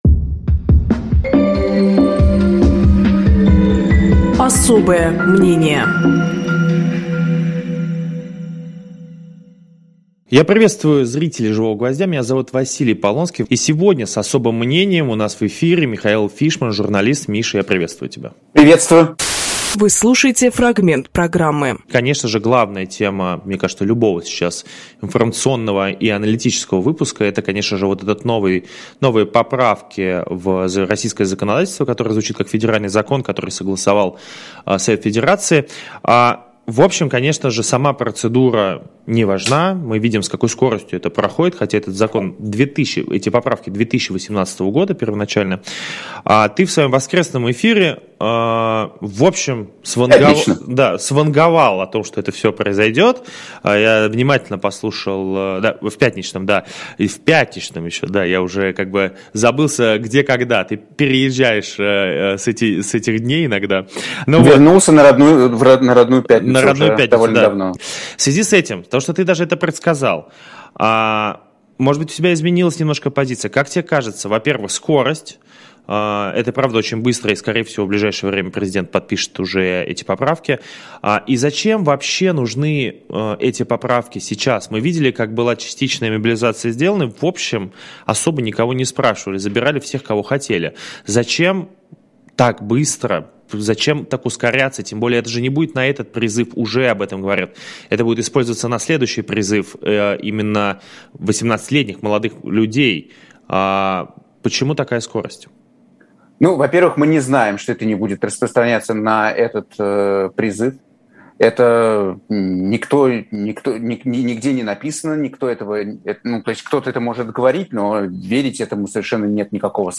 Фрагмент эфира от 12.04